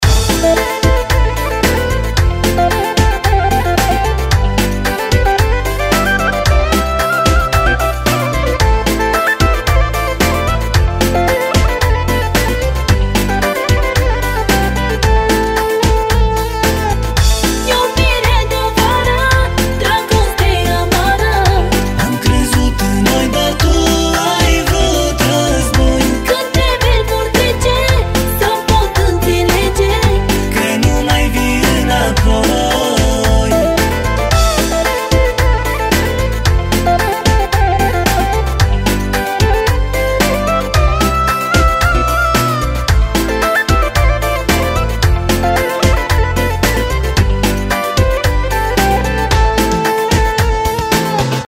• Качество: 320, Stereo
Новая композиция в лучших традициях восточной музыки